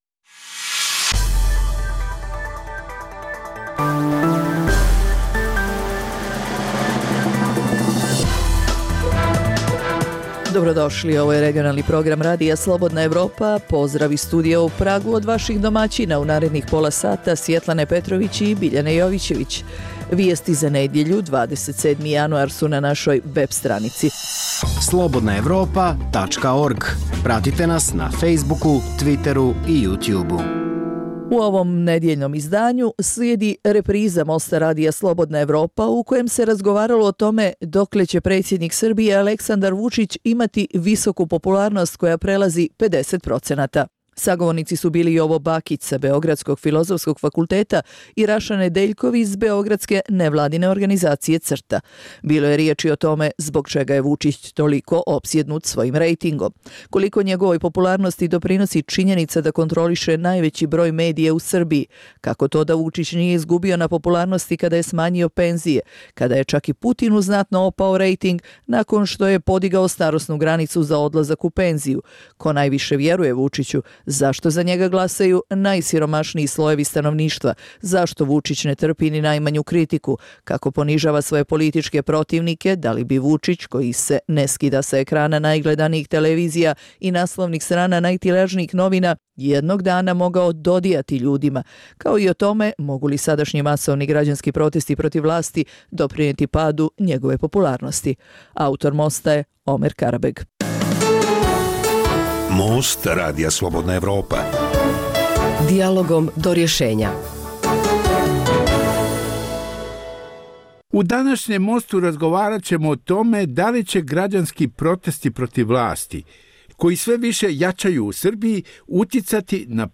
u kojem ugledni sagovornici iz regiona razmatraju aktuelne teme. Drugi dio emisije čini program "Pred licem pravde" o suđenjima za ratne zločine na prostoru bivše Jugoslavije.